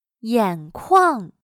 眼眶/Yǎnkuàng/cavidad del ojo